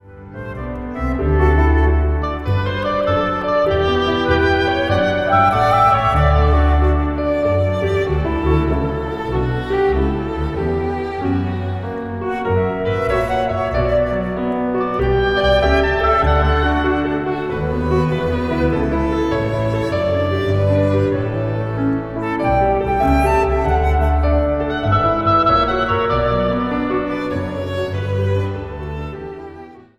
hobo
orgel/piano.
Instrumentaal | Contrabas
Instrumentaal | Dwarsfluit
Instrumentaal | Harp
Instrumentaal | Viool